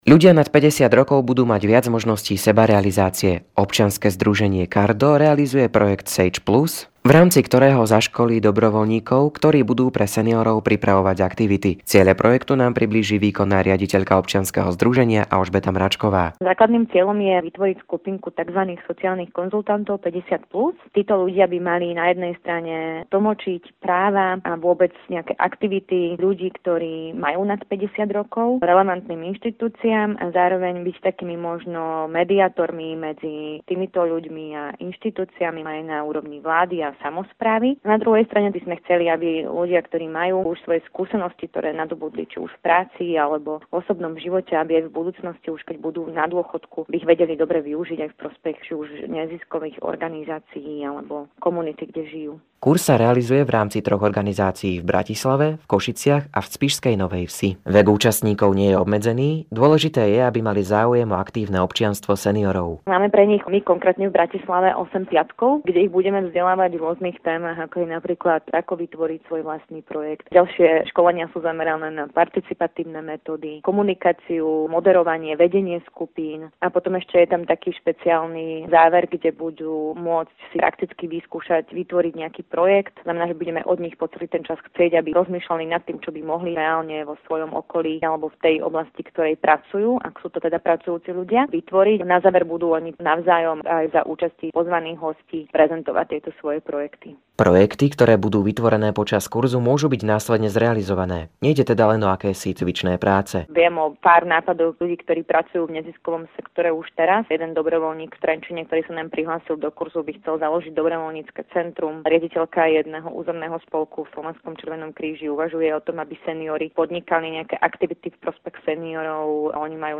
Príspevok bol zverejnený v Rádiu Lumen